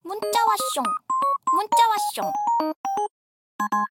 알림음 8_문자왔숑.ogg